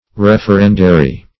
Referendary \Ref`er*en"da*ry\ (r?f`?r*?n"d?*r?), n. [LL.